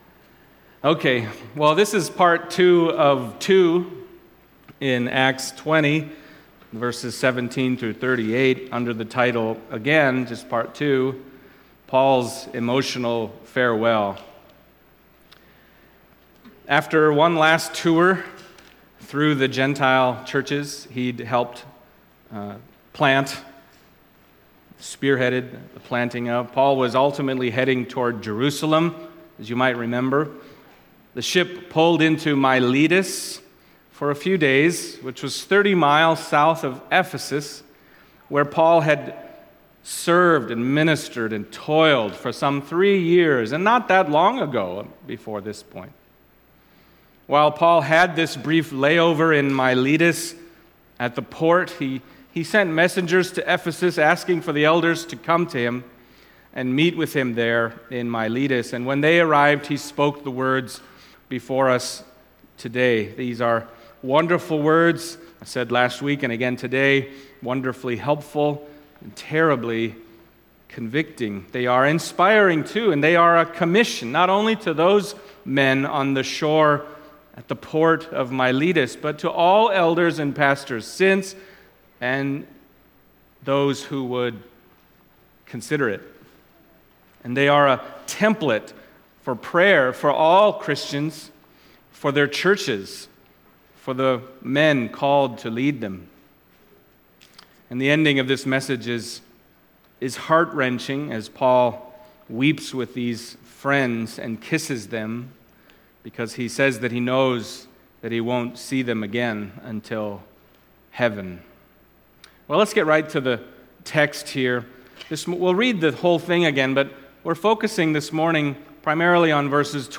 Acts Passage: Acts 20:17-38 Service Type: Sunday Morning Acts 20:17-38 « Paul’s Emotional Farewell